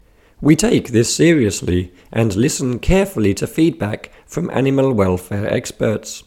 DICTATION 5